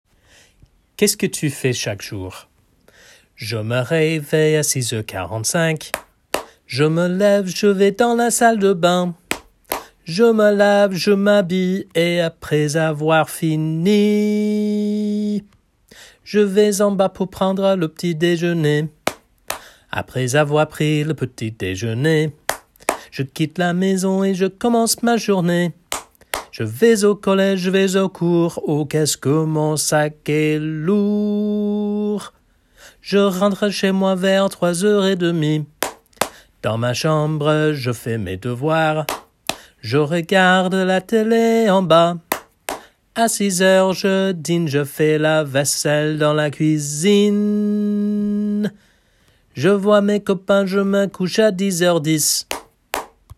chanson
song